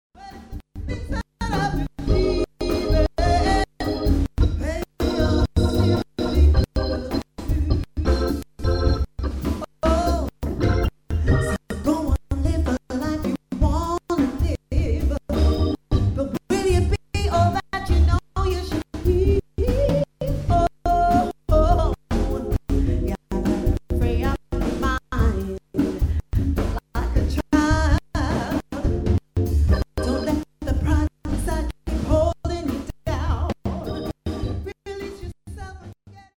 Sounds like the needle is jumping out of the groove.